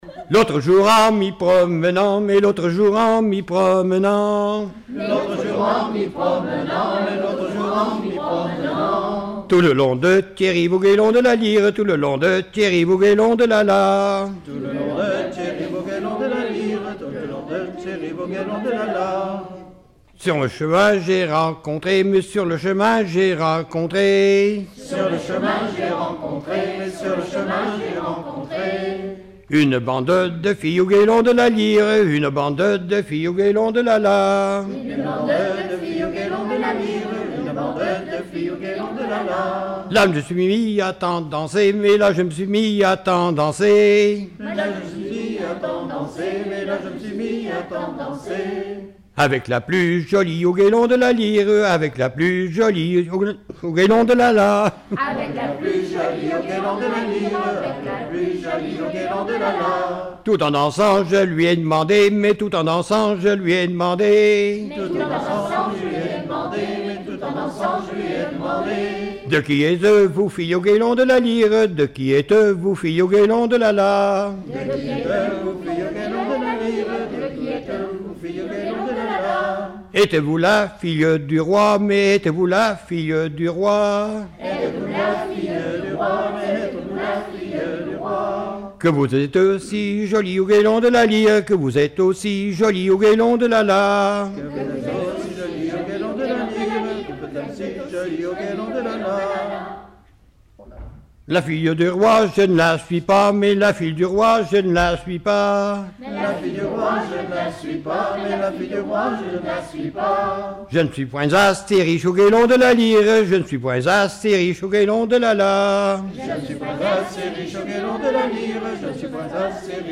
danse : ronde : grand'danse
Collectif-veillée (1ère prise de son)
Pièce musicale inédite